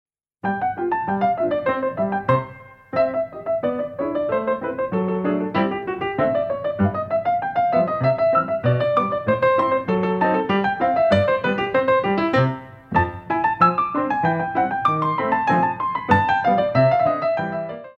Batterie ou sauts face à la barre